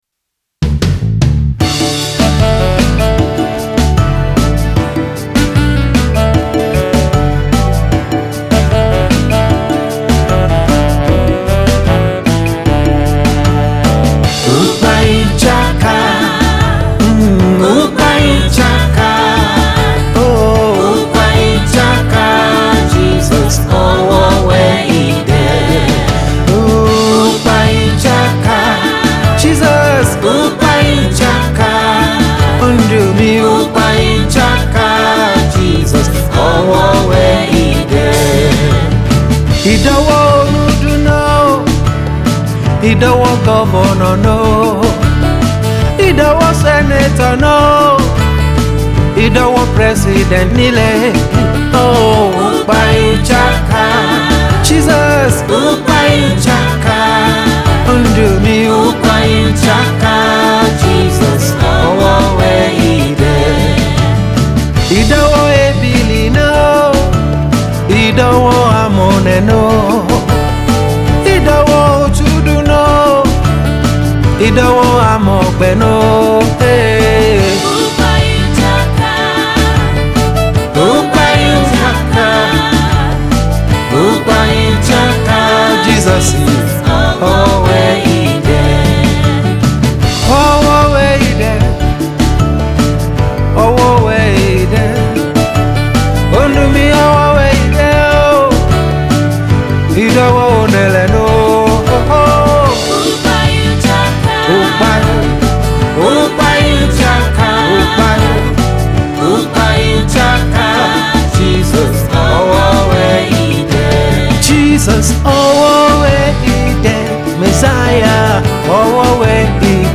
Genre: Worship.